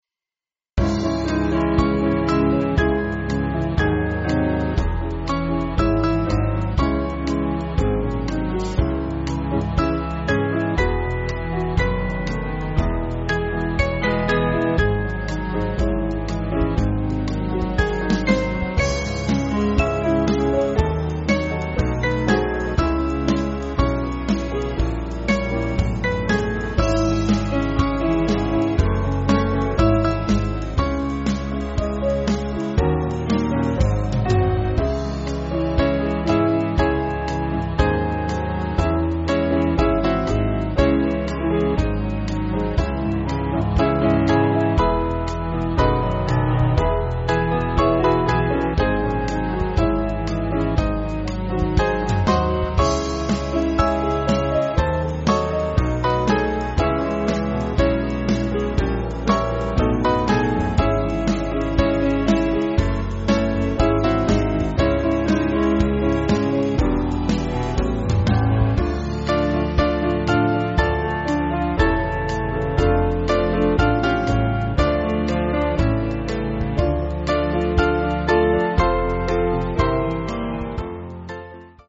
African American spirtual
Small Band
(CM)   3/Dbm-Dm